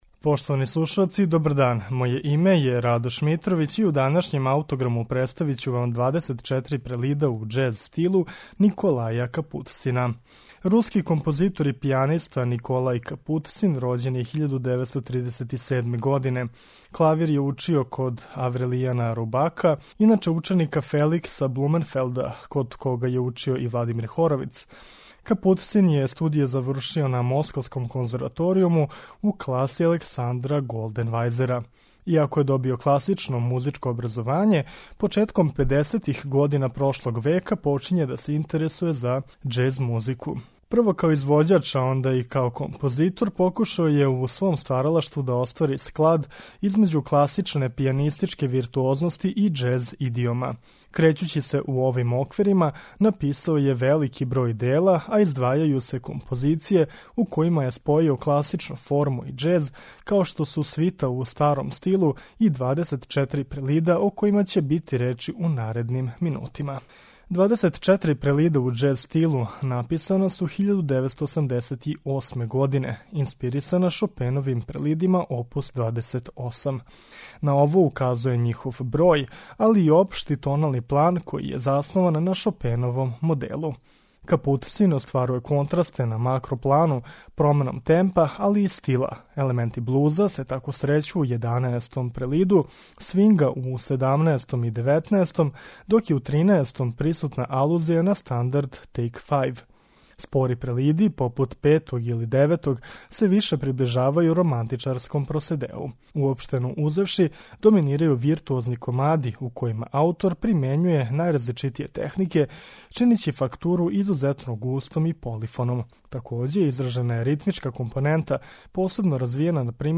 представља спој џез музике и неоромантичарског израза.